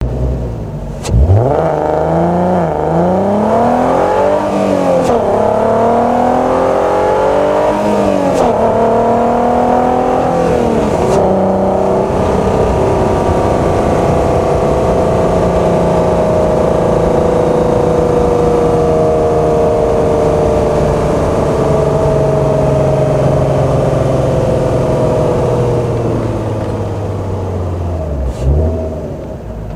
Added underhood mic (mp3) to exhaust mics
r32-two-mics1.mp3